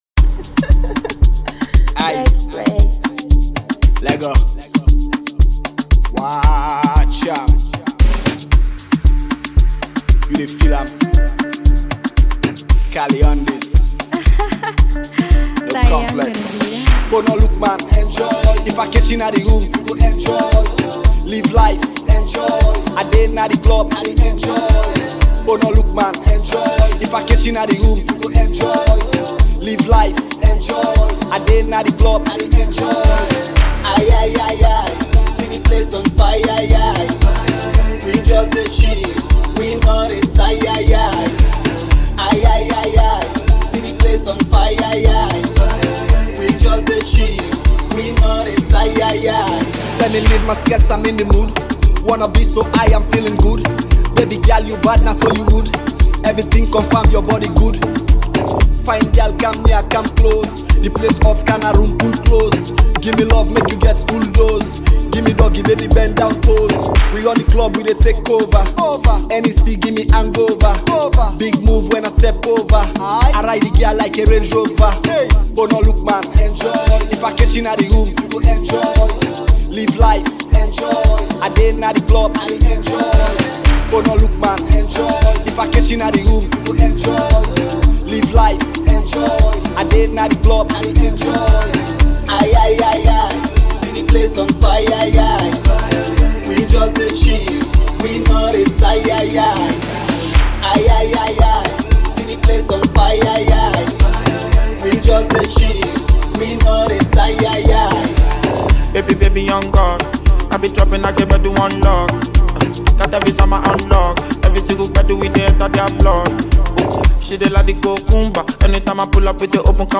afro-pop song